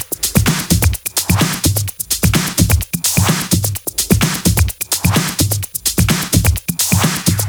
VFH1 128BPM Big Tee Kit 2.wav